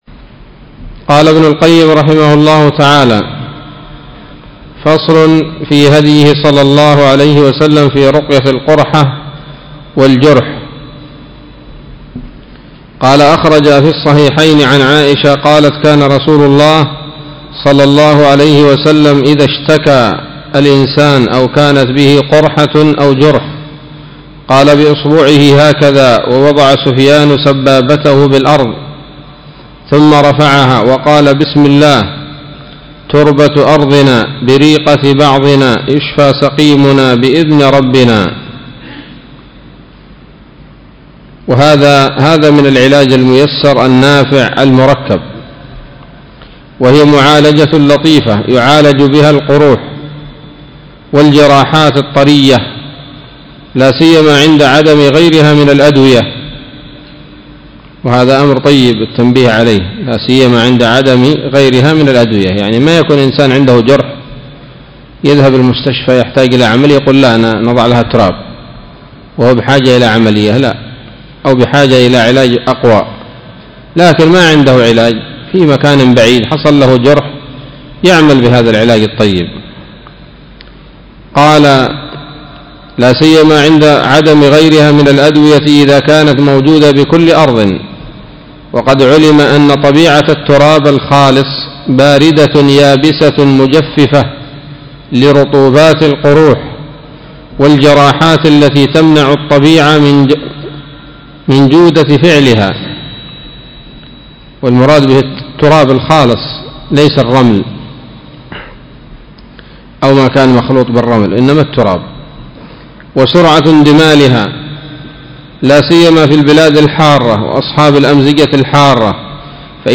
الدرس الثالث والخمسون من كتاب الطب النبوي لابن القيم